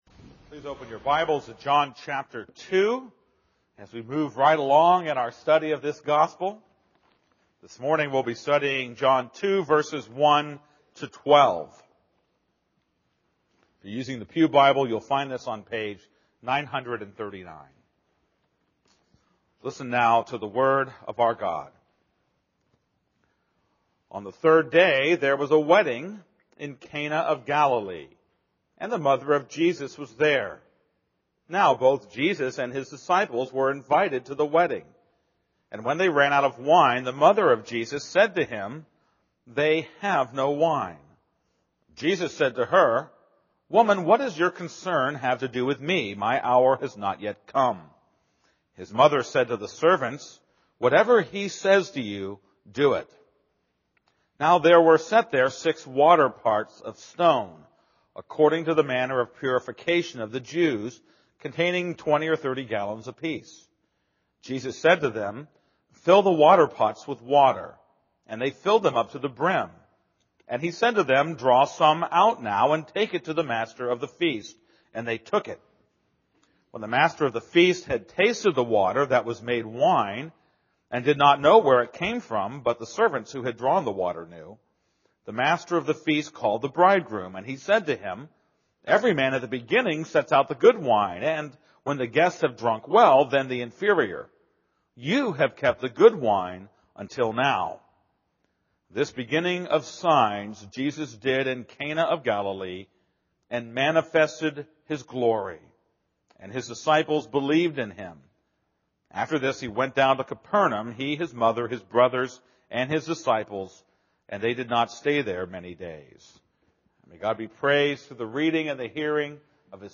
This is a sermon on John 2:1-12.